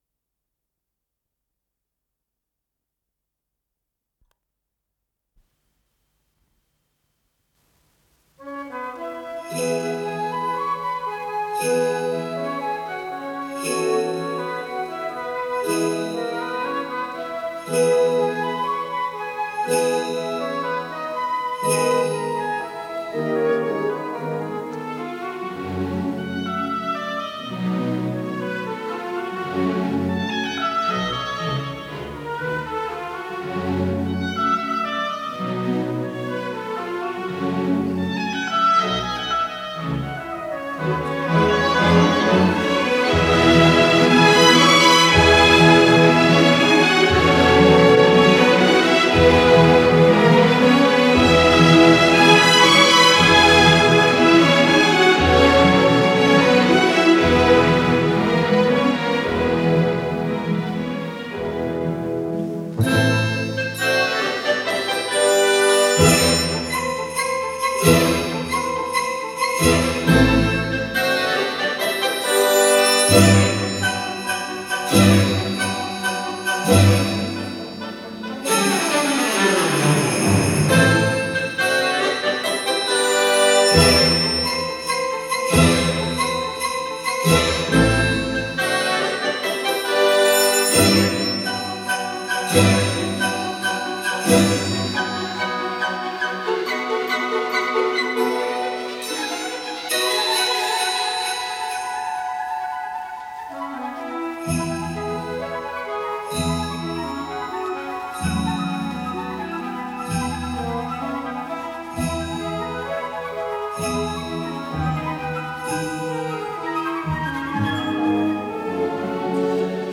с профессиональной магнитной ленты
Авторы версииАлександр Гаук - оркестровка
ИсполнителиГосударственный академический симфонический оркестр СССР
Дирижёр - Евгений Светланов